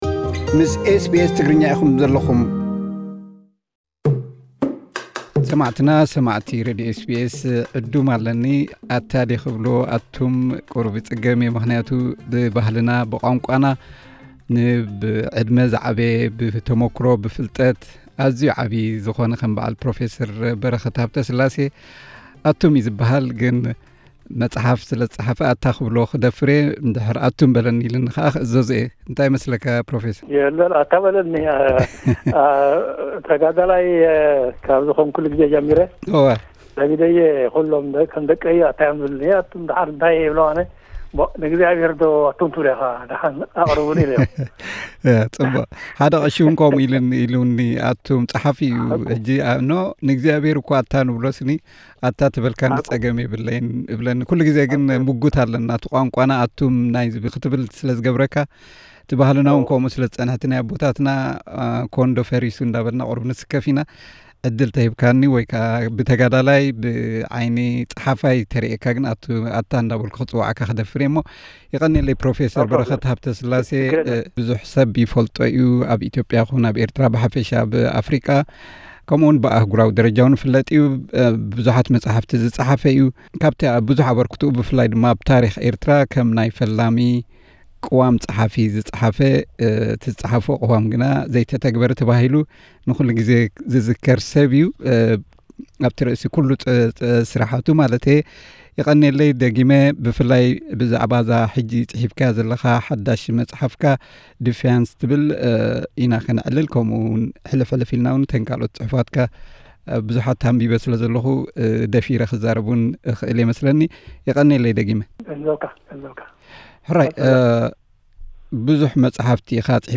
ብምኽንያት ኣብዚ ቅሩብ መዓልታት ዝጸሓፋ መጽሓፍ “Defiance” ምኽንያት ብምግባር ምስ ሬድዮ SBS ትግርኛ ዝገብሮ ዕላል።